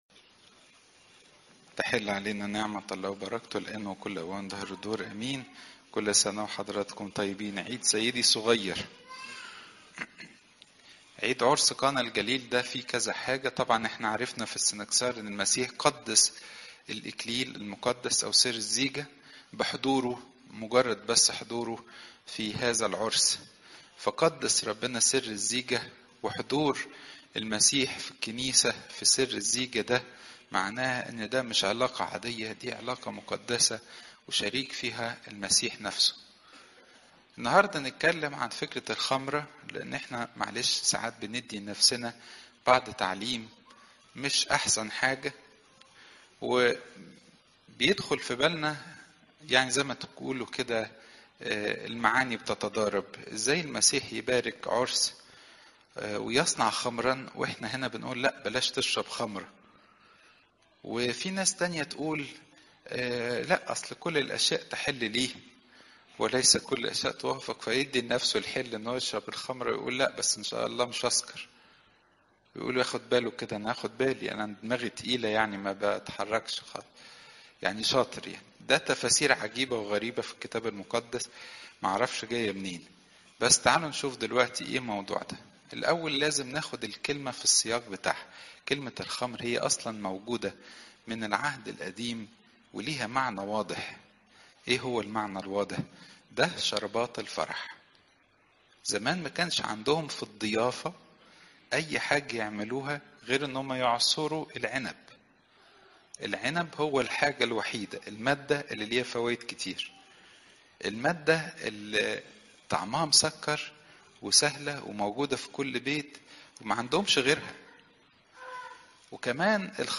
عظات المناسبات عيد عرس قانا الجليل (يو 2 : 1 - 11)